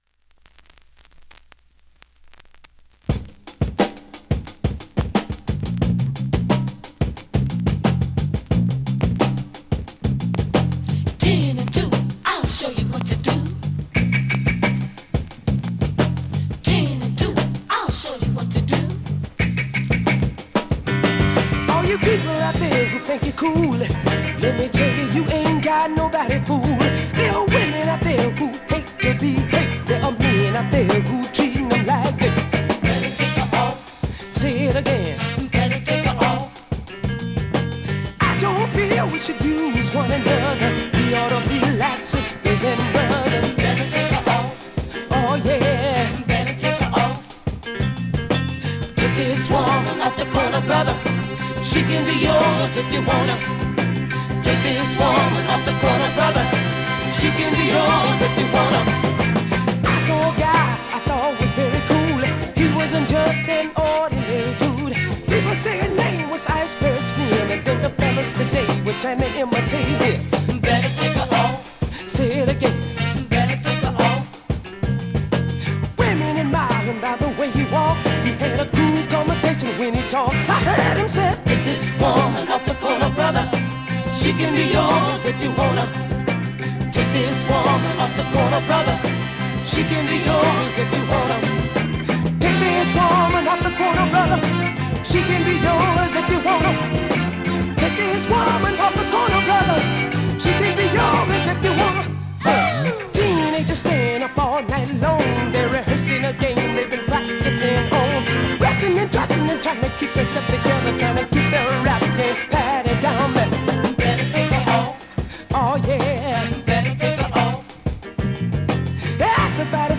現物の試聴（両面すべて録音時間６分４４秒）できます。
ドラムブレイクで幕を開ける ナイスファンク/ソウルトラック!